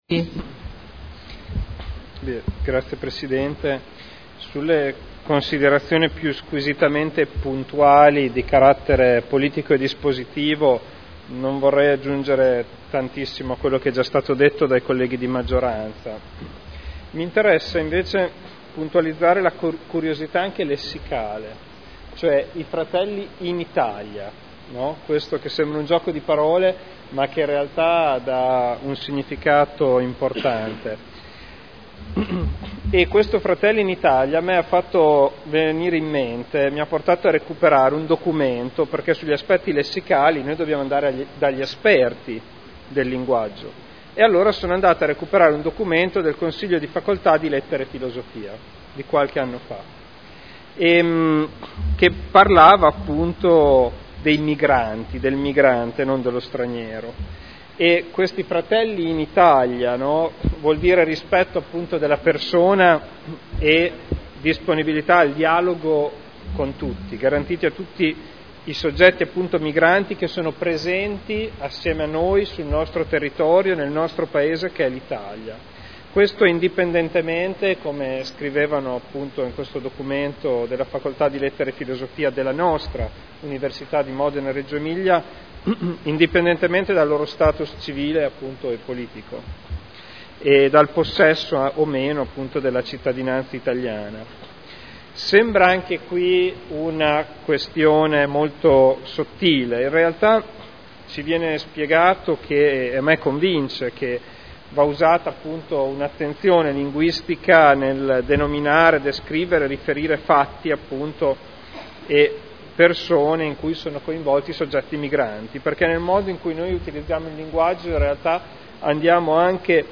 Federico Ricci — Sito Audio Consiglio Comunale
Mozione presentata dai consiglieri Prampolini, Artioli, Trande, Garagnani, Goldoni, Pini, Glorioso, Gorrieri, Sala, Guerzoni, Rocco, Codeluppi, Cotrino, Campioli, Cornia, Morini, Rimini, Rossi F., Dori (P.D.) avente per oggetto: “Fratelli in Italia” Dichiarazioni di voto